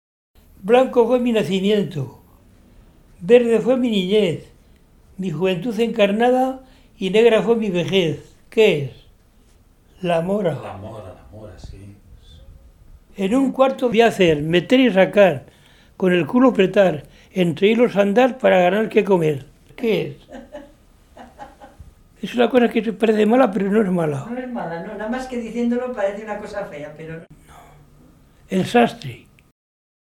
Clasificación: Adivinanzas
Lugar y fecha de recogida: Alcanadre, 26 de febrero de 2004